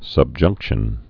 (səb-jŭngkshən)